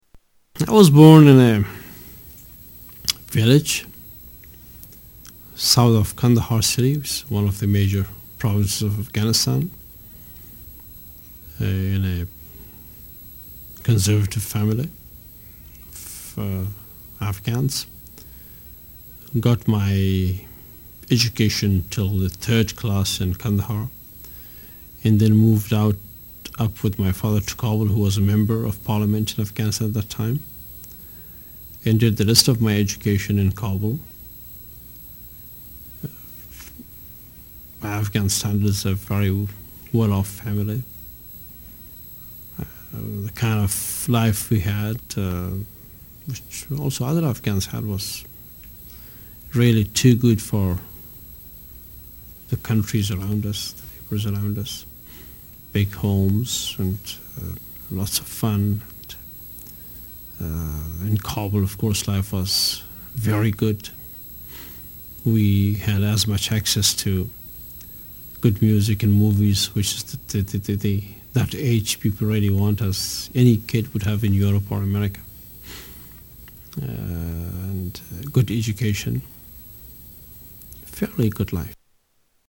Tags: Political Hamid Karzai audio Interviews President Afghanistan Taliban